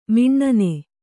♪ miṇṇane